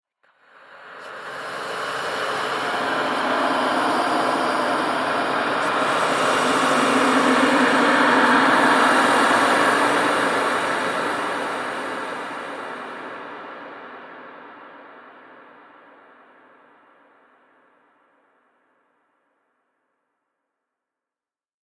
Шипение призрака жуткое
shipenie_prizraka_zhutkoe_z6t.mp3